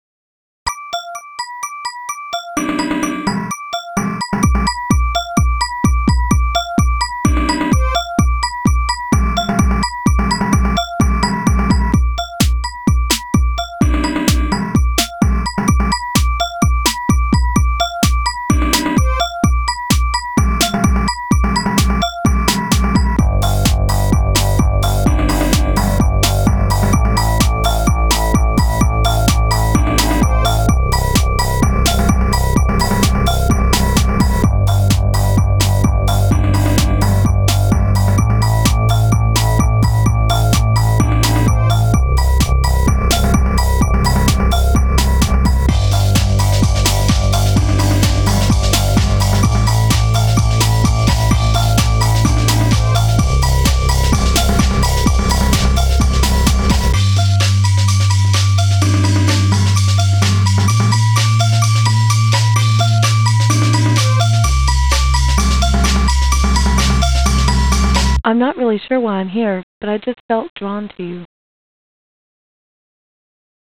Alt...Working on some tracks sampling Dark Seed (videogame) music